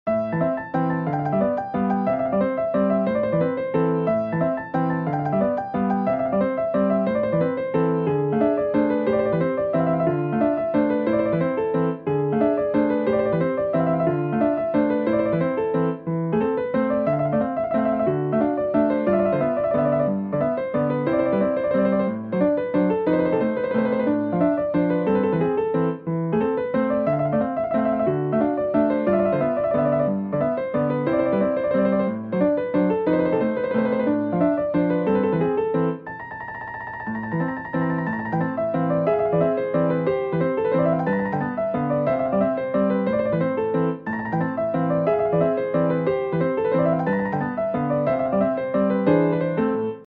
Müəllif: Azərbaycan Xalq Rəqsi